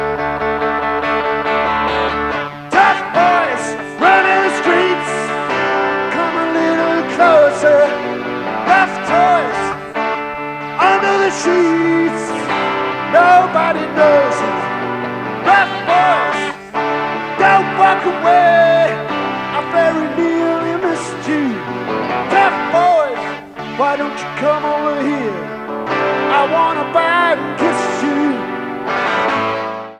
DAT Tape Masters